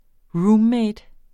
Udtale [ ˈɹuːmˌmεjd ]